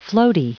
Prononciation du mot floaty en anglais (fichier audio)
Prononciation du mot : floaty